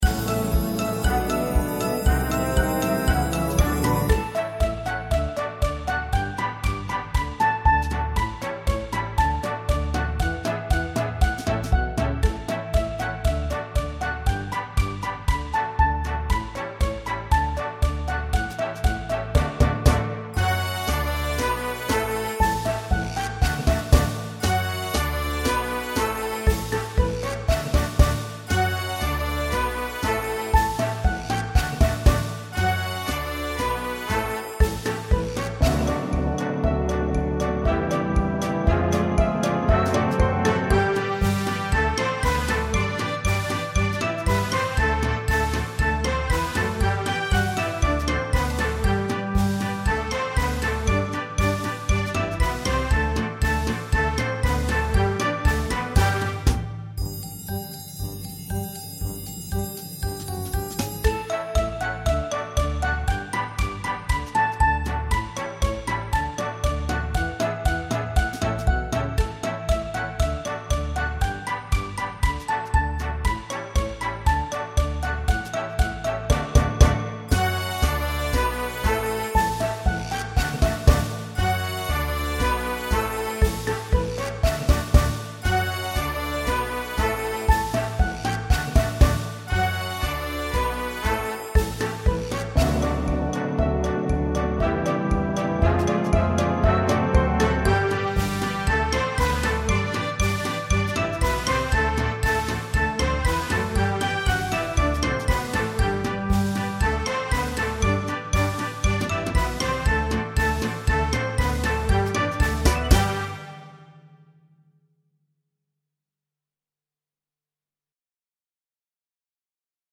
10. Can Can (Backing Track)